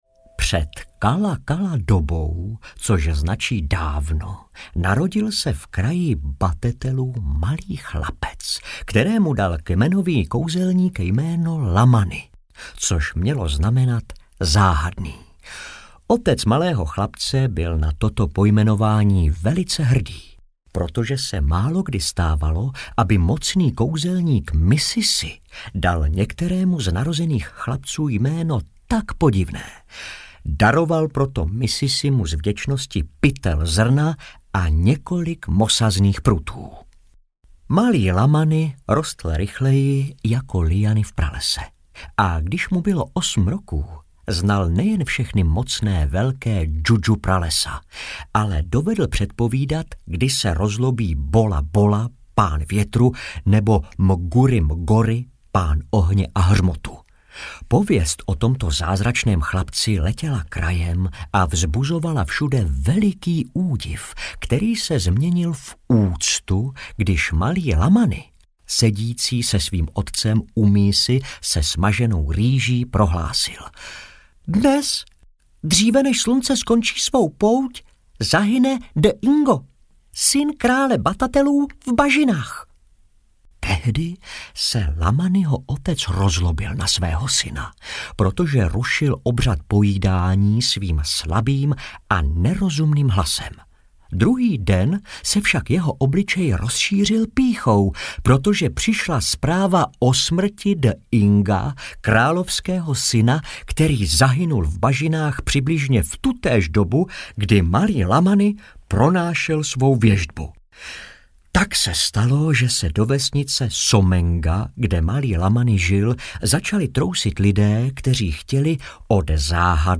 Prales kouzelných snů audiokniha
Ukázka z knihy
• InterpretJaroslav Plesl, Saša Rašilov, Lukáš Hlavica